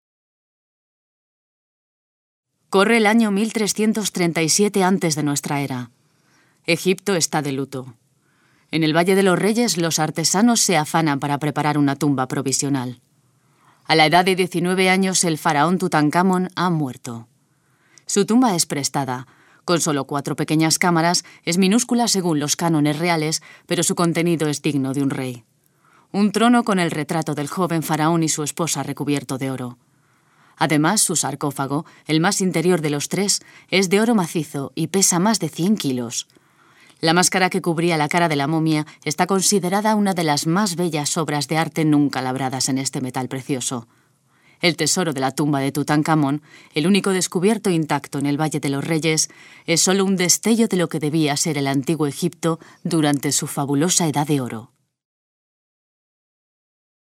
Voz cálida y expresiva.
kastilisch
Sprechprobe: eLearning (Muttersprache):